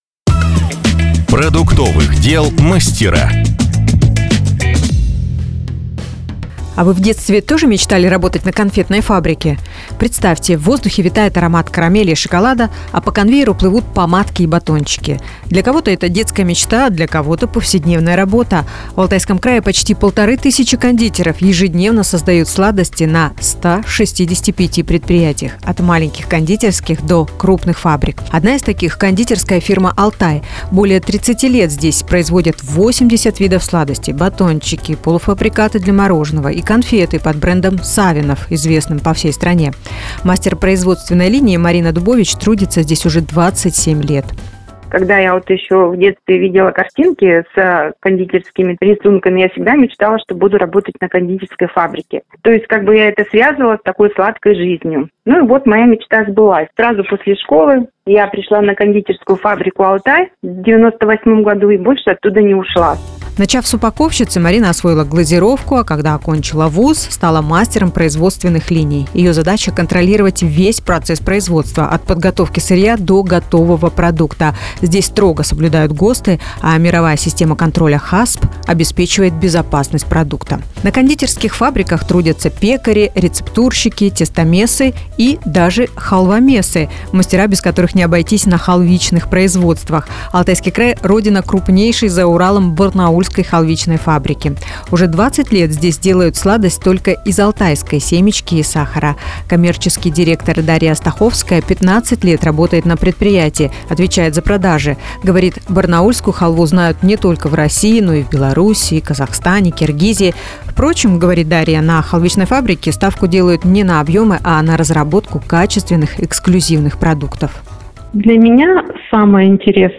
Сюжет на Business FM (Бизнес ФМ) Барнаул